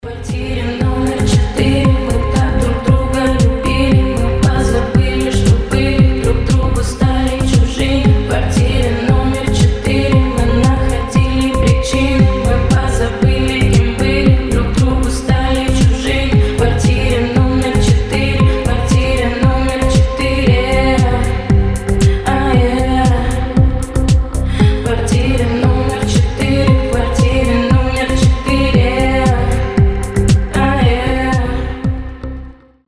поп
deep house
грустные
атмосферные